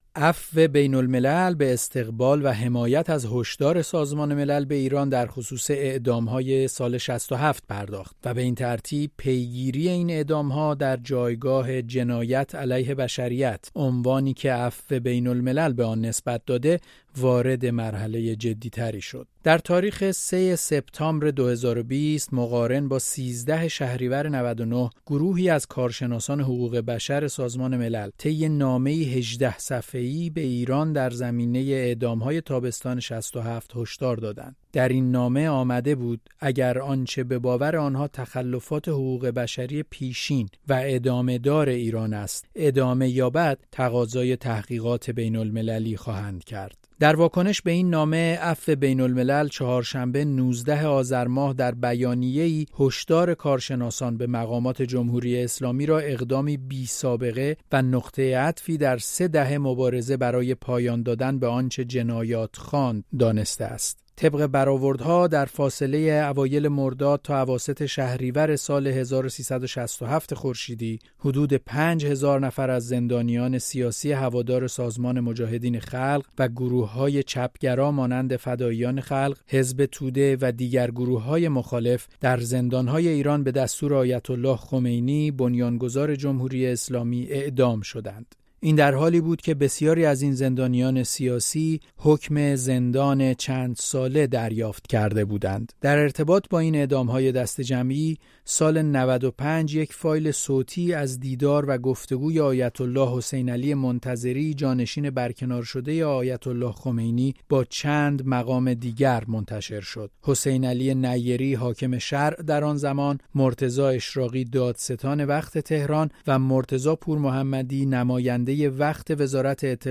جزئیات را در گزارشی